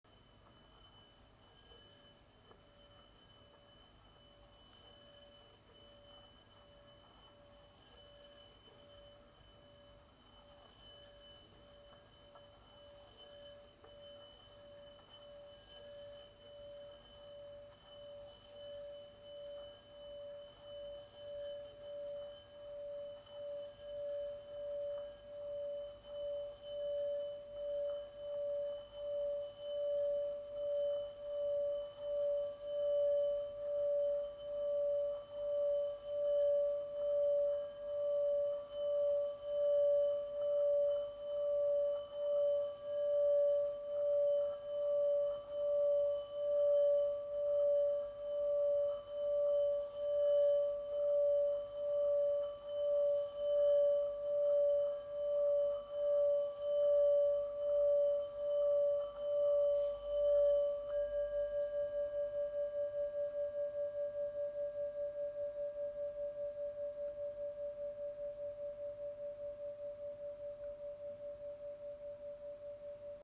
Lyssna på vibrationerna från den 10 cm stora tibetanska skålen Chö-Pa
cm med en klubba med en diameter på 28 mm:
De regelbundna vibrationerna uppstår efter cirka 30 sekunder
vibration-bol-chantant.m4a